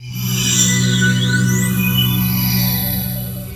CHORD48 01-L.wav